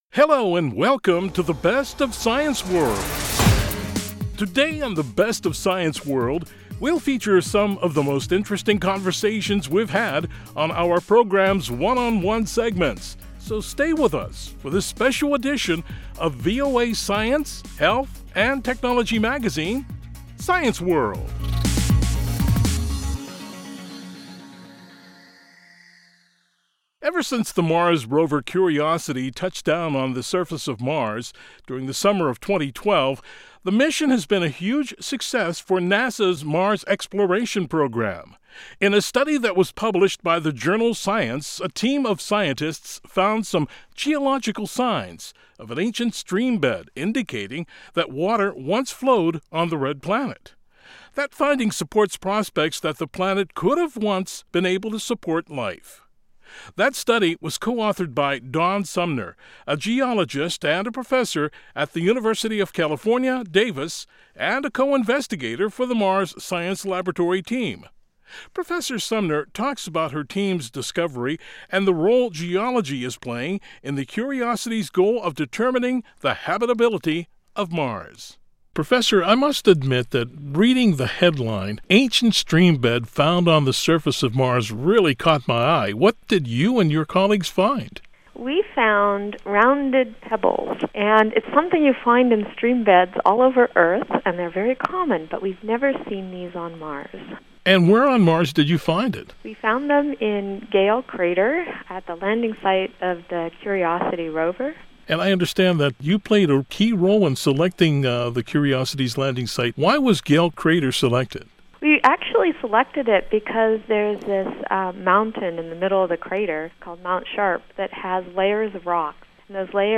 Today we'll feature some of the most interesting conversations we've had on our program's ‘One on One’ segments.